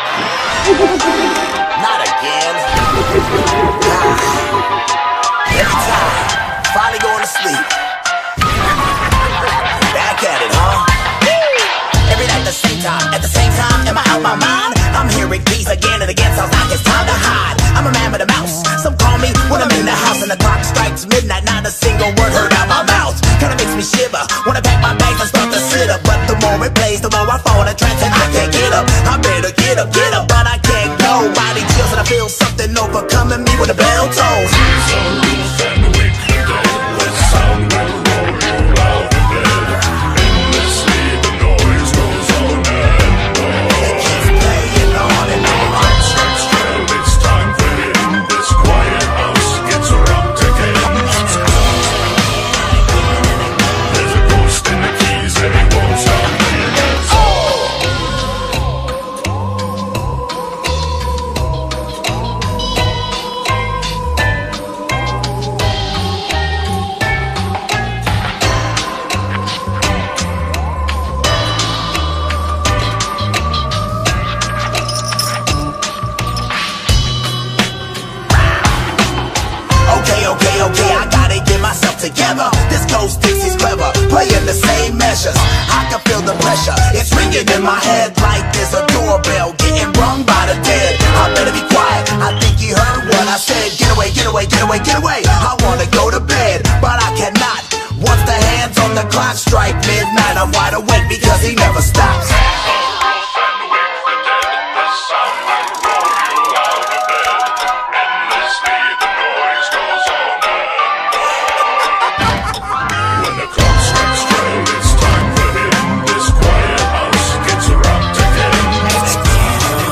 BPM85
MP3 QualityMusic Cut